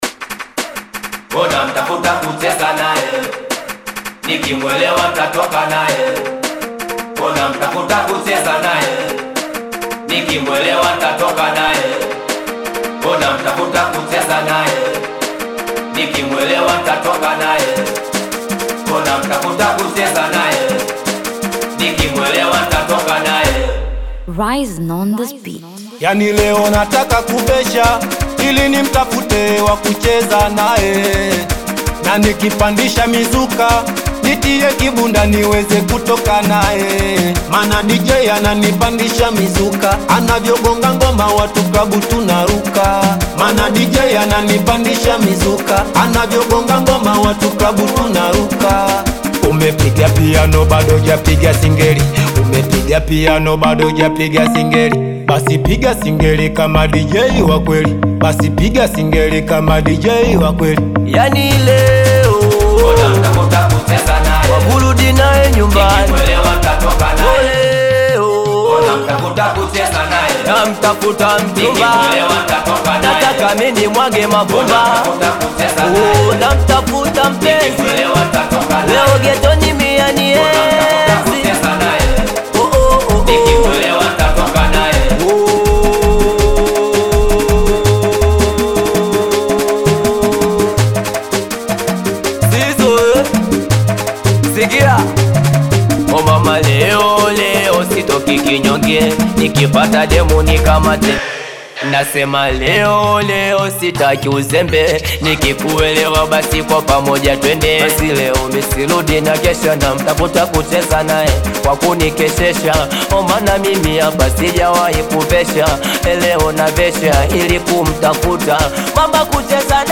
beautiful and sparkling tune